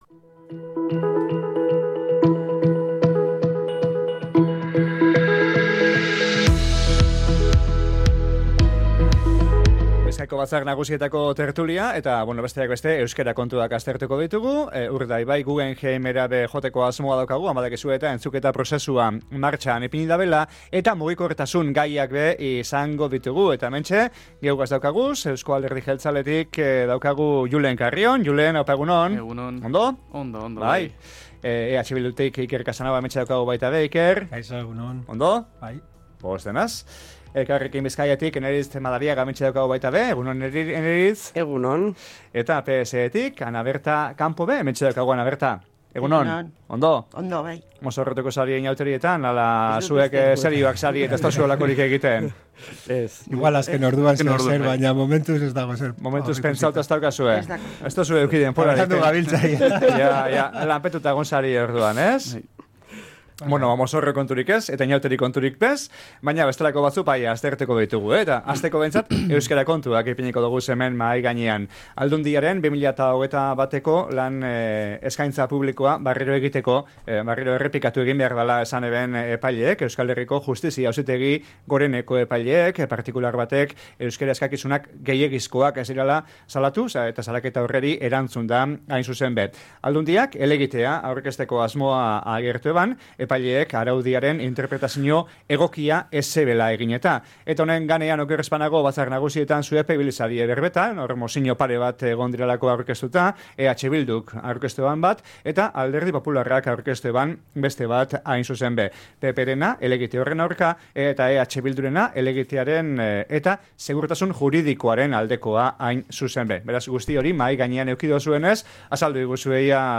Euskerea eta Urdaibaiko Guggenheimen entzute prozesua Batzar Nagusien gaurko tertulian | Bizkaia Irratia
Aldundiaren 2021eko LEP prozesuaren judizializazinoaz emon deuskue eritxia batzarkideek